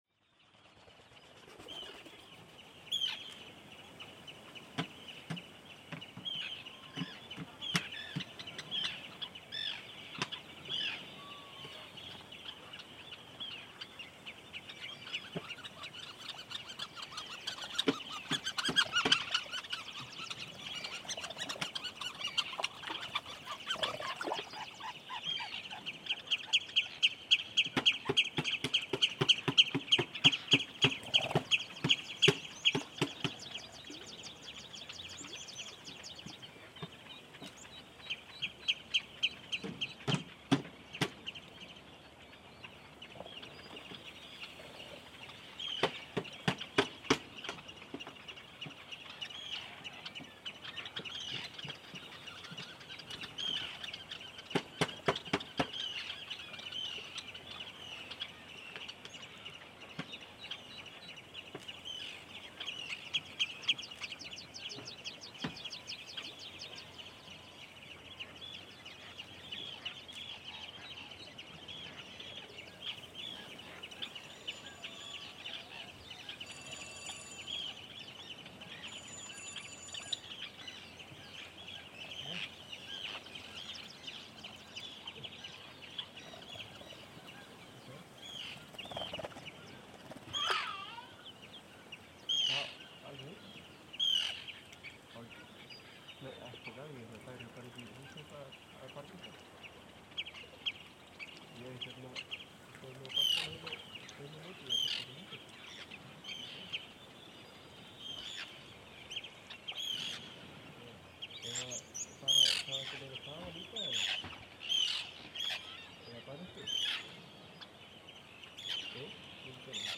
Black legged Kittiwake at Arnarstapi
One of this recordings I made was above a cliff score near Arnarstapi .
I expanded my boom over the cliff brink and located the microphone in a calm place.
About 15-20 meters below, the sea wave smoothly stroke the cliffs. Whine black legged Kittiwake flew all around and the cliffs were full of the their tweedy baby’s. During one hour recording I was totally raped on the cliff edge in this beautiful ambiance. Close by in next score, fishermens were working at the dock and some tourist walked by behind me.
Í bakgrunni má hyra í erlendum ferðamönnum staldra við, spjalla og taka myndir.